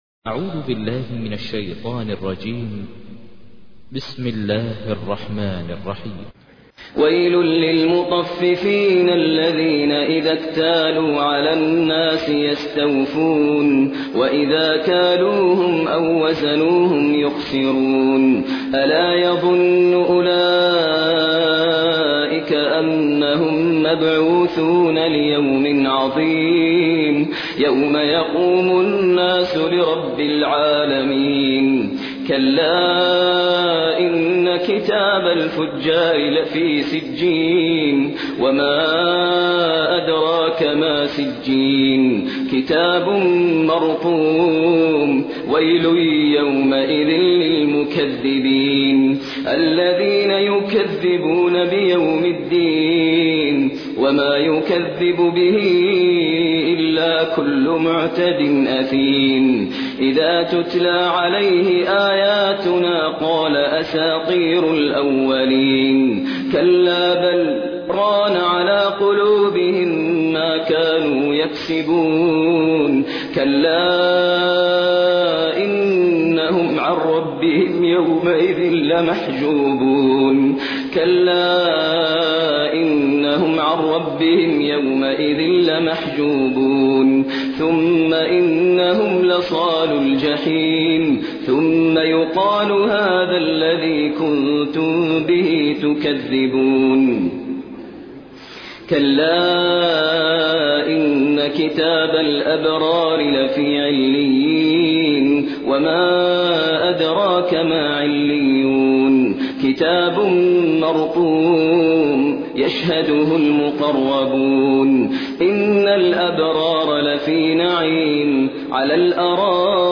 تحميل : 83. سورة المطففين / القارئ ماهر المعيقلي / القرآن الكريم / موقع يا حسين